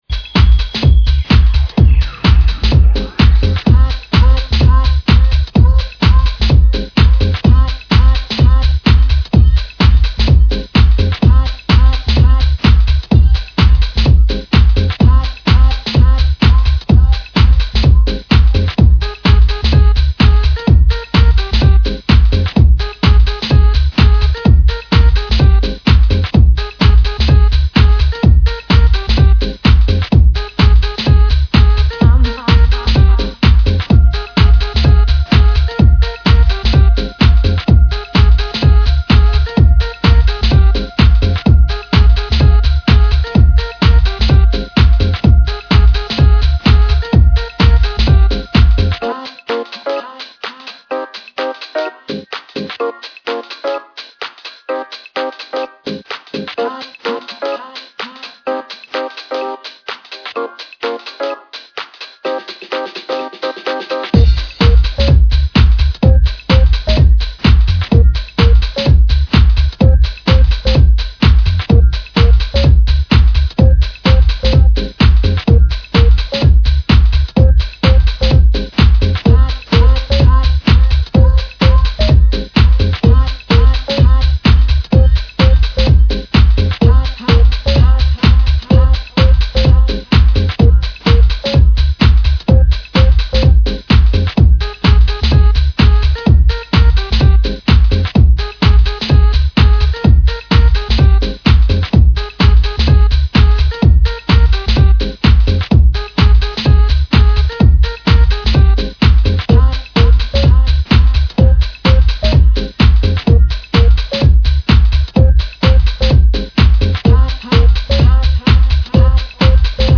Vibrant Synths and Buoyant House Rhythms
Four Dancefloor Ready Musings